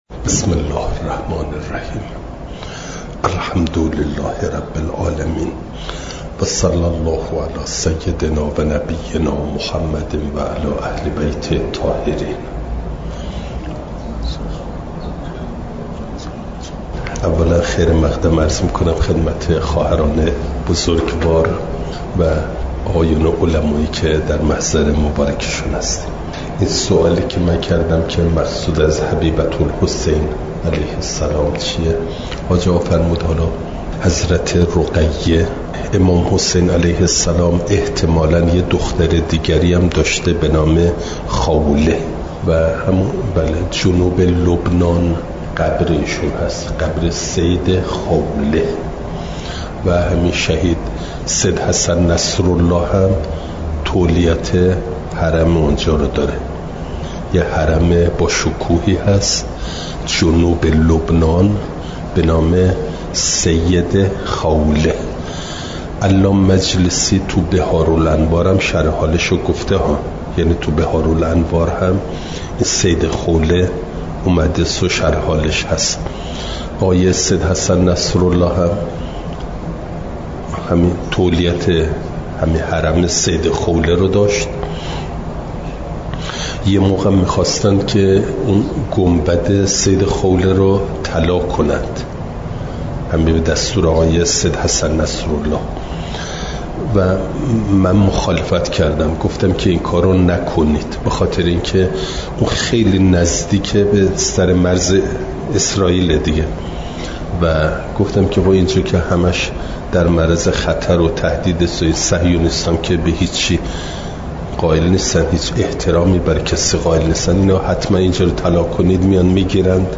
شنبه ۱۲ مهرماه ۱۴۰۴، دارالقرآن علامه طباطبایی(ره)